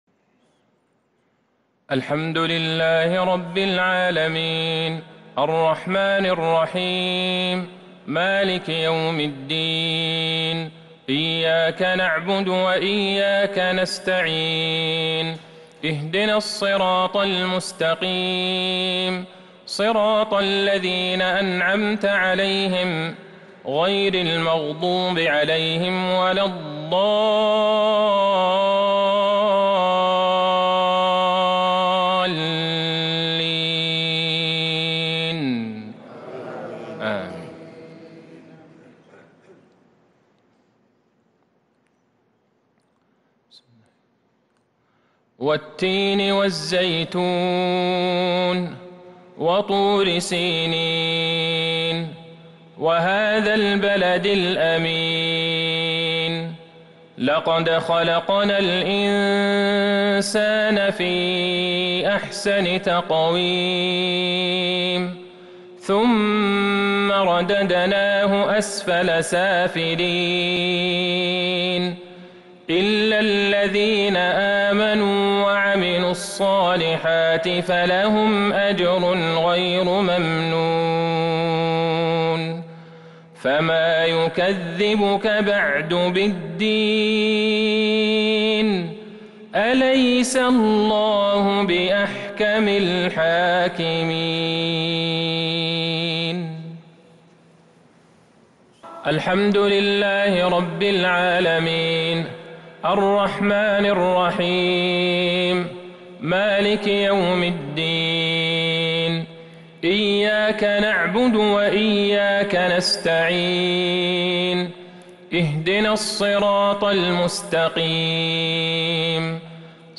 صلاة المغرب للقارئ عبدالله البعيجان 5 ذو الحجة 1442 هـ
تِلَاوَات الْحَرَمَيْن .